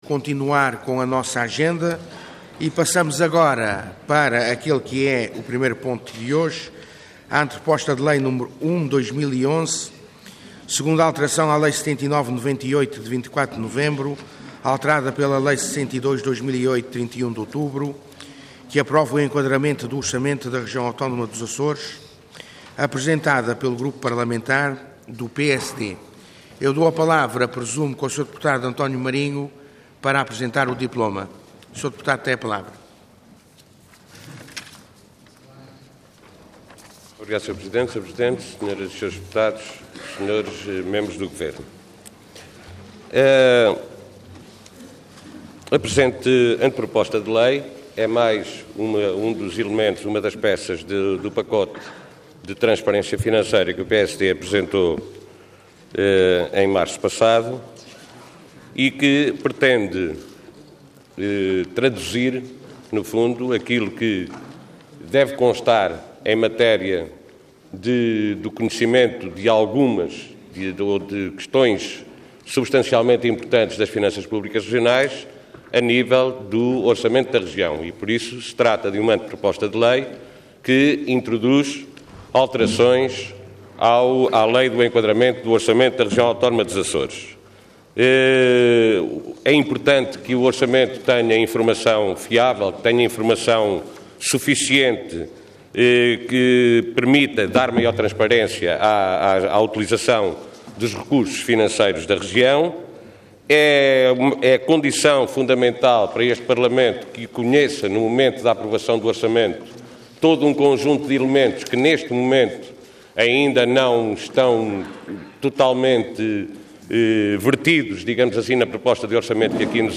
Intervenção Anteproposta de Lei Orador António Marinho Cargo Deputado Entidade PSD